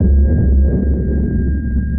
sonarPingSuitMediumShuttle1.ogg